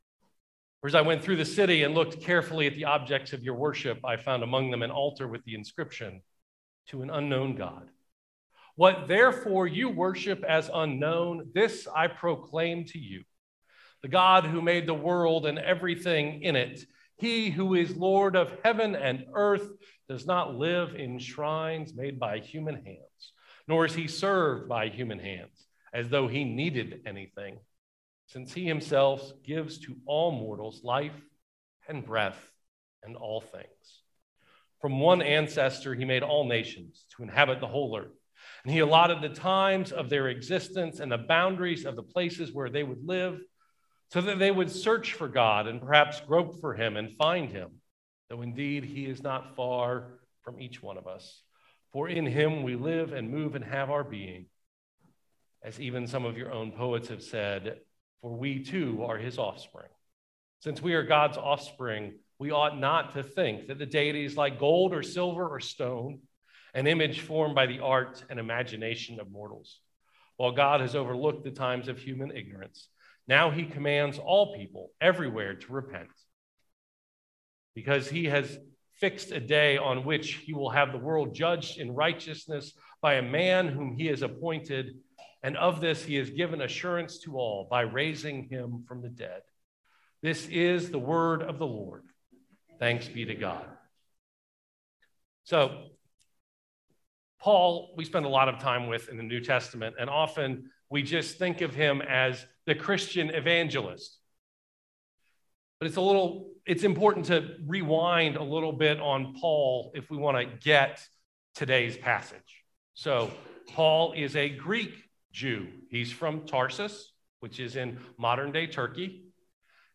Worship